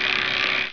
BIKESOUNDclick.wav